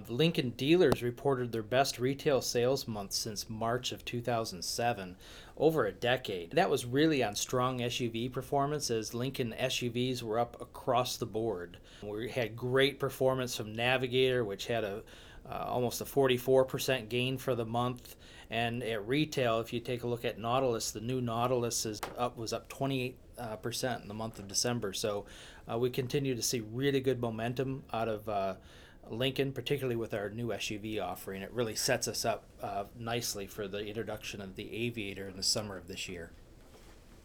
Soundbites: December and Full-Year Sales